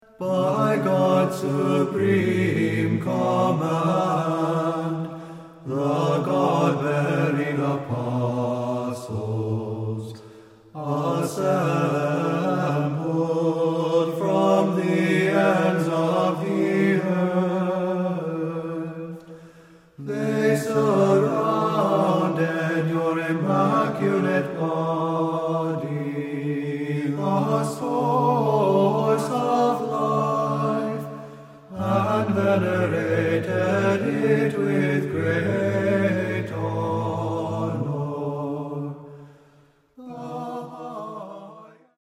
Great Znamenny--All 8 Tones